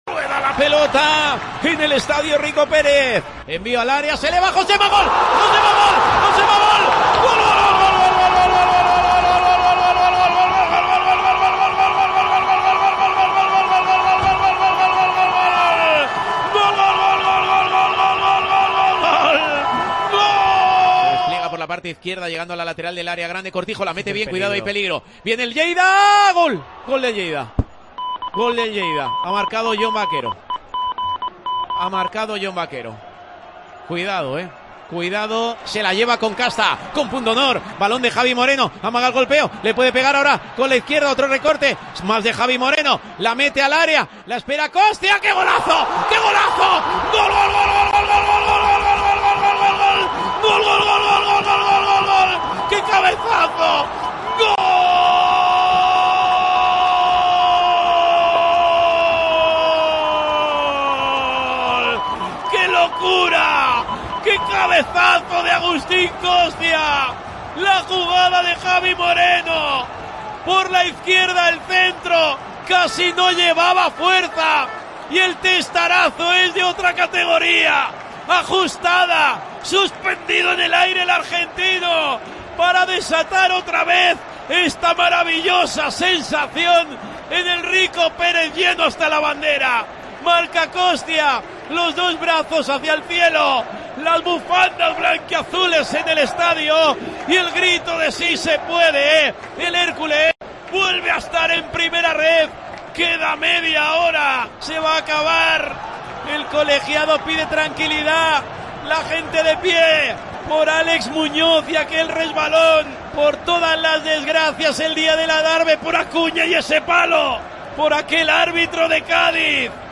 En un audio recopilatorio de nuestros compañeros de COPE Alicante se puede apreciar cómo se vivió en Tiempo de Juego con Paco González el ascenso de este histórico equipo del fútbol español a la tercera división del fútbol español.
Resumen de sonidos del ascenso del Hércules en Tiempo de Juego